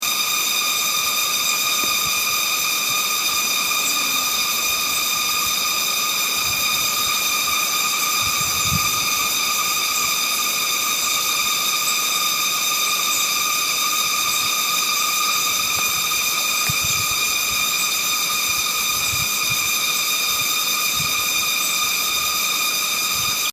Bei einem kurzen Stopp zur Beseitigung eines biologischen Problems wurden unsere Ohren heute unglaublich malträtiert. Zikaden machten einen Höllenlärm, den ich aufnahm.
Zikaden.mp3